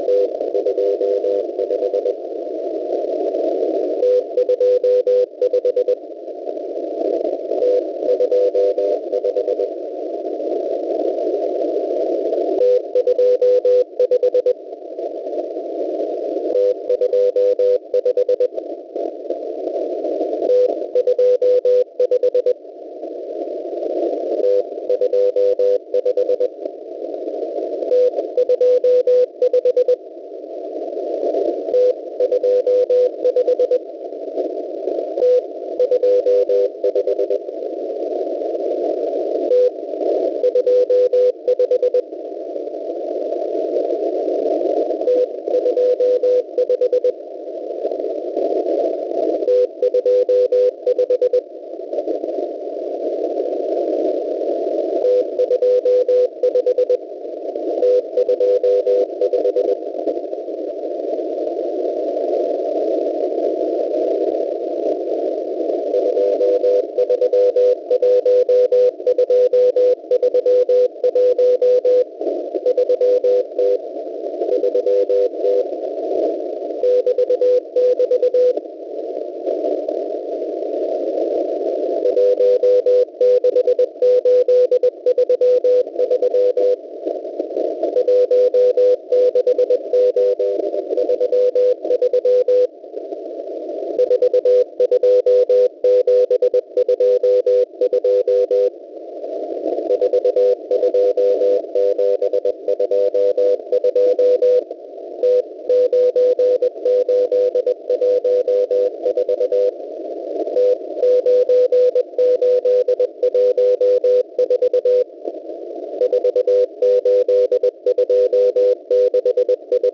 5280 khz